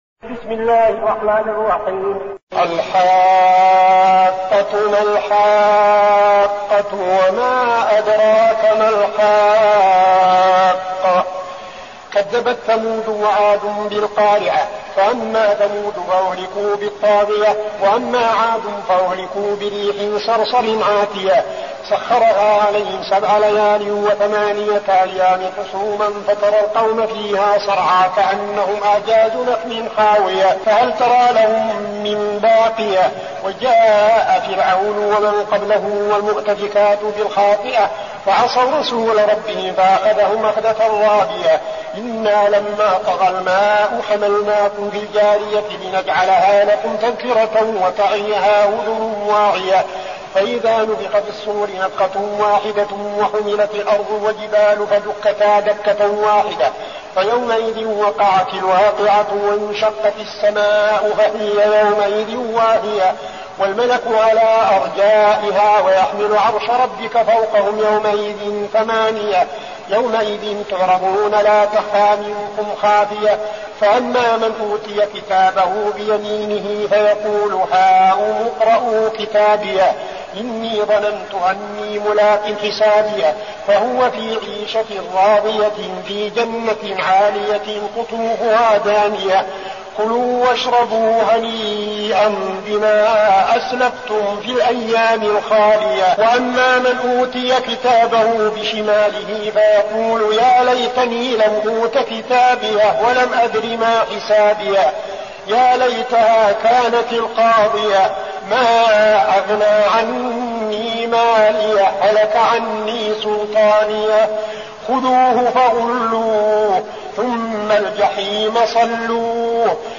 المكان: المسجد النبوي الشيخ: فضيلة الشيخ عبدالعزيز بن صالح فضيلة الشيخ عبدالعزيز بن صالح الحاقة